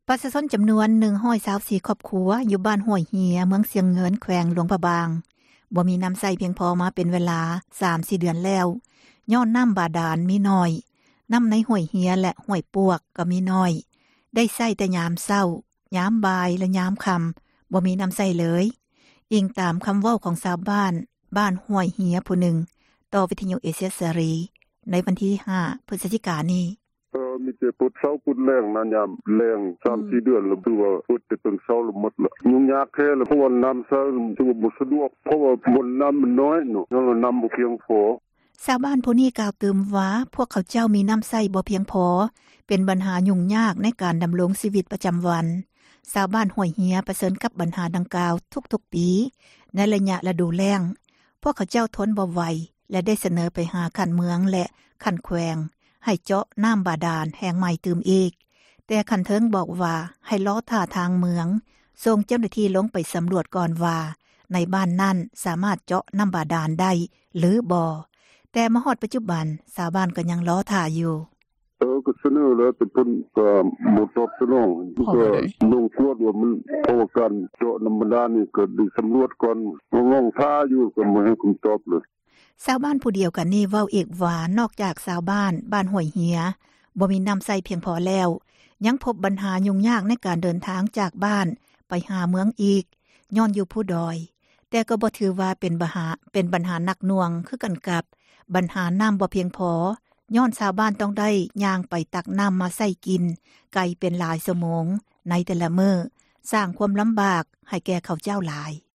ນໍ້າໃນຫ້ວຍເຮັຽ ແລະ ຫ້ວຍປວກ ກໍ ມີໜ້ອຍ ໄດ້ໃຊ້ ແຕ່ຍາມເຊົ້າ ຍາມບ່າຍ ແລະ ຍາມຄໍ່າ ບໍ່ມີນໍ້າໃຊ້ເລີຍ, ອີງຕາມຄໍາເວົ້າ ຂອງຊາວບ້ານ ບ້ານຫ້ວຍເຮັຽ ຜູ້ນຶ່ງ ກ່າວຕໍ່ວິທຍຸ ເອເຊັຽເສຣີ ໃນວັນທີ່ 5 ພຶສຈິການີ້: